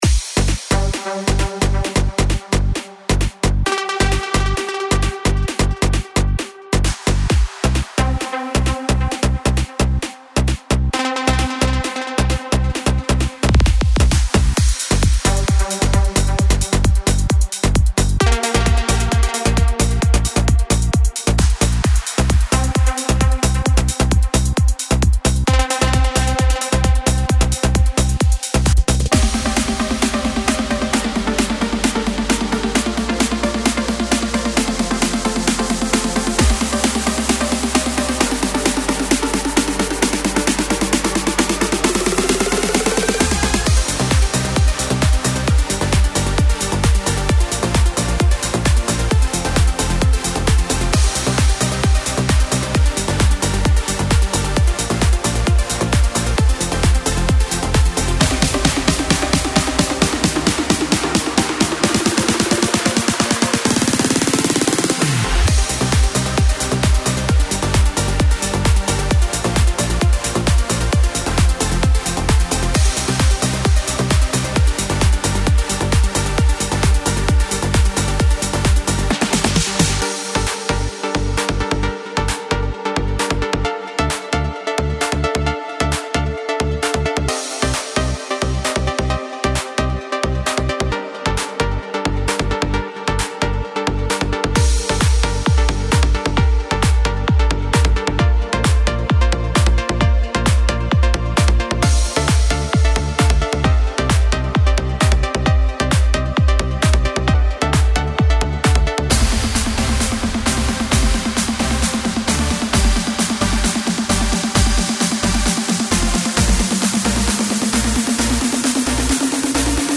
המקצבים